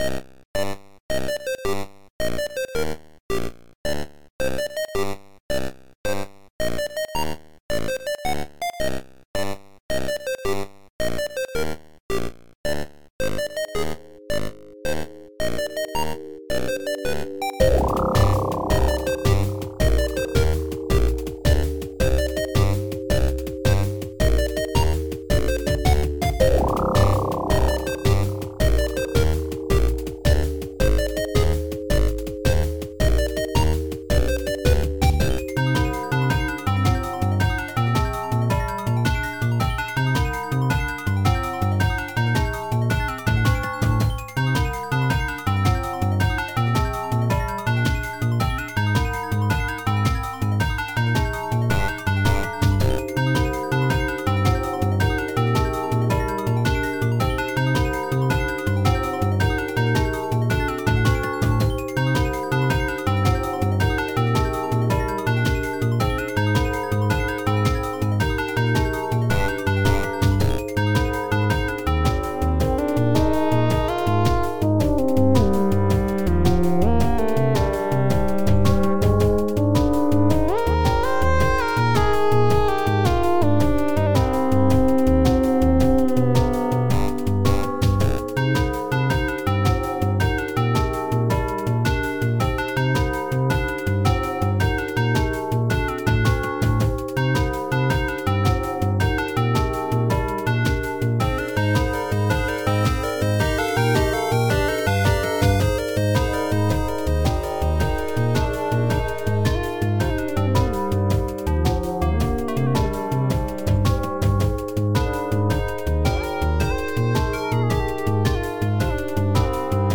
A tune that starts as a beepy march then becomes an offbeat jaunt in 6/8 time. As a Sega Genesis track, it features a contrast of smooth FM synthesis and PSG beepiness.